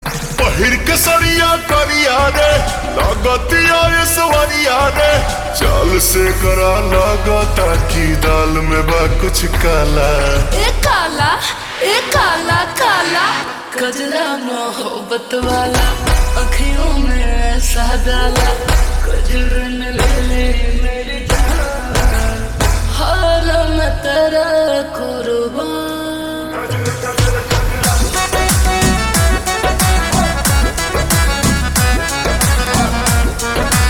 Bhojpuri Songs
• Simple and Lofi sound
• Crisp and clear sound